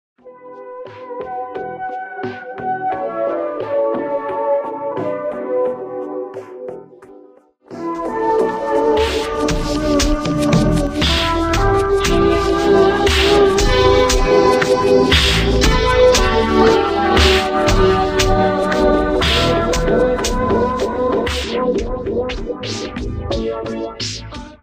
sound / ambience / ambivapor1.ogg
ambivapor1.ogg